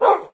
bark1.ogg